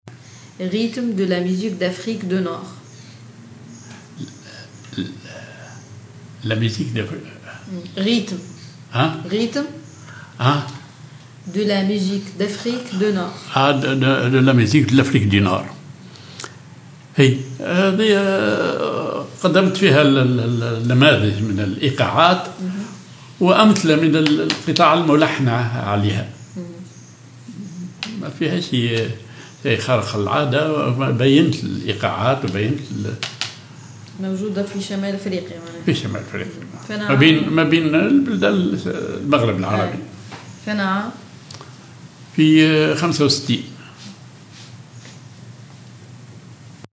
Rythmes de la musique d afrique de nord
Rhythms of the music of North Africa